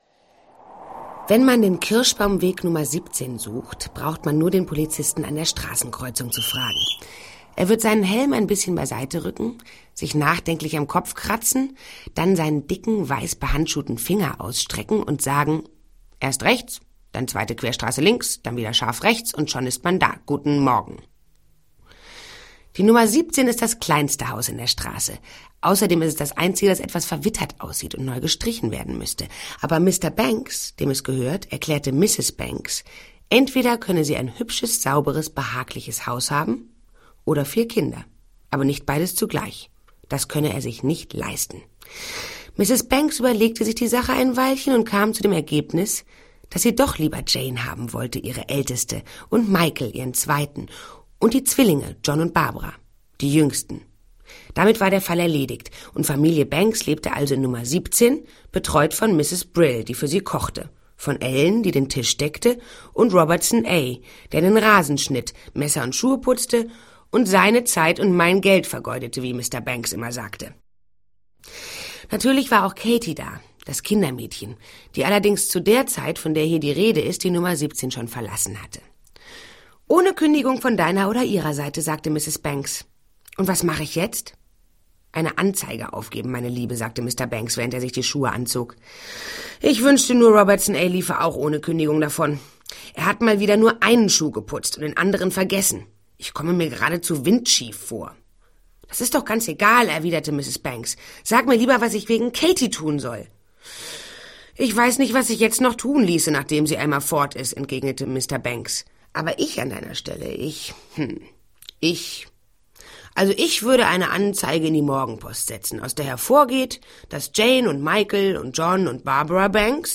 Rufus Beck , Charles Brauer , Rosemarie Fendel , Benno Fürmann , Heike Makatsch , Ulrich Noethen , Josefine Preuß , Udo Wachtveitl (Sprecher)
Gekürzte Lesung